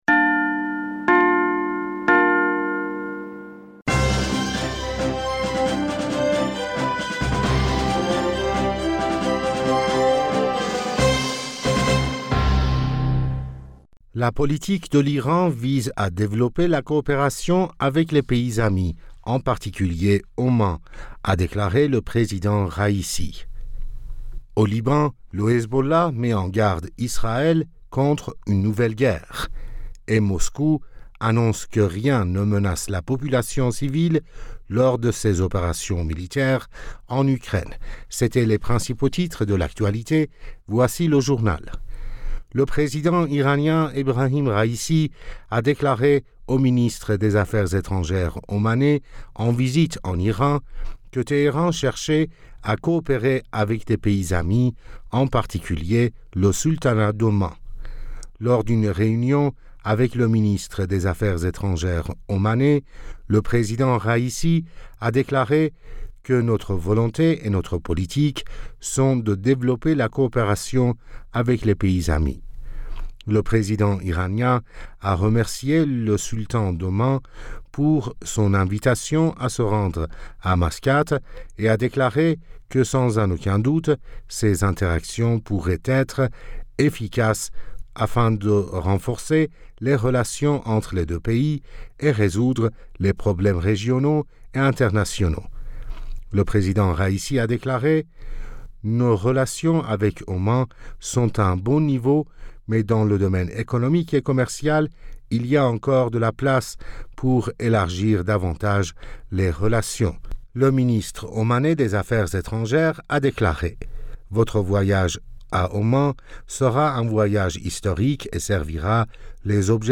Bulletin d'information Du 24 Fevrier 2022